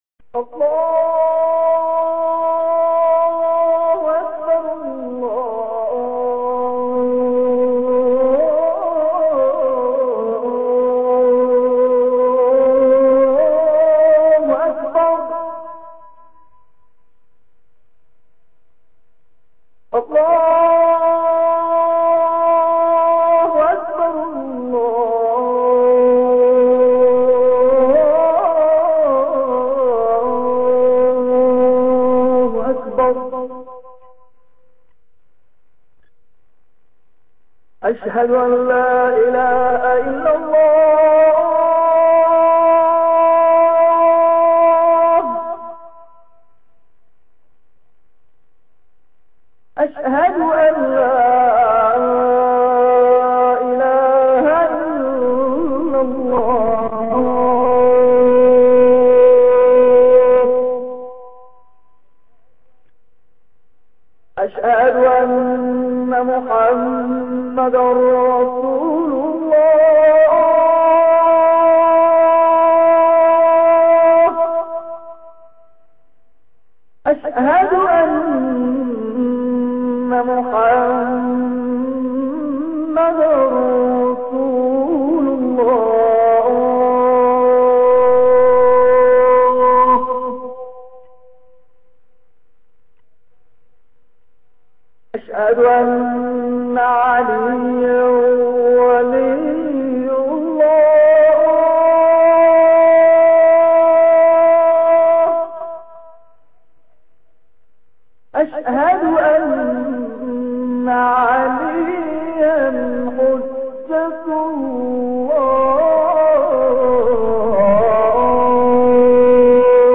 اذان بنده سال 59 ضبط شد که این اذان را در مقام حجاز اجرا کردم.
این اذان را فی‌البداهه خواندم. حضرت آقا فرمودند من در مورد اذان شما با یک متخصص موسیقی صحبت کردم و گفتند این اذان در دستگاه همایون یا همان حجاز خوانده شده که این سبک و سیاق در سال‌های بسیار دور نیز در مدینه شنیده شده است.
اذان